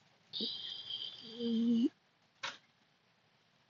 Eeeee Sound Button - Bouton d'effet sonore